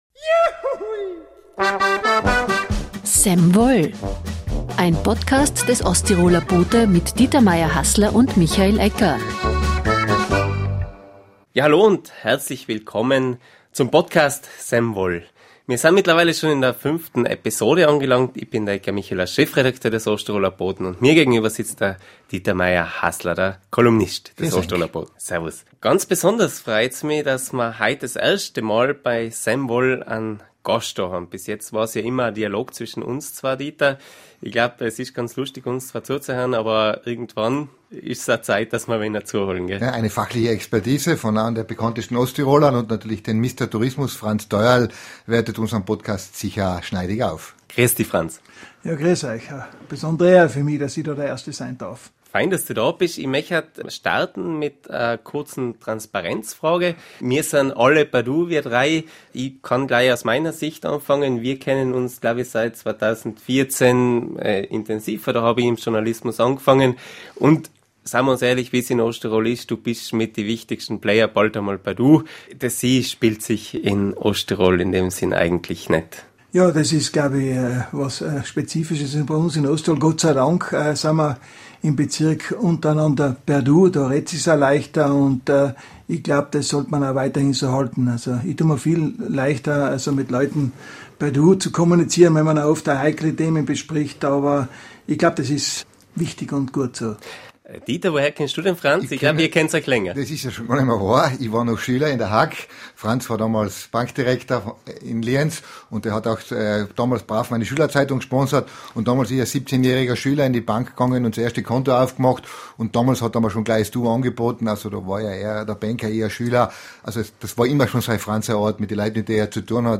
im Talk.